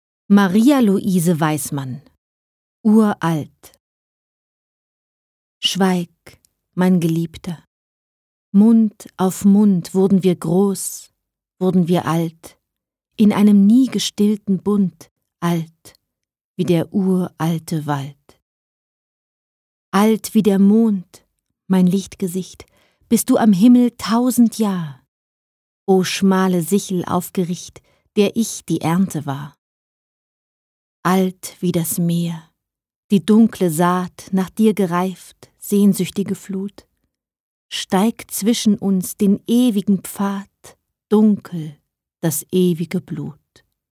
Lyrik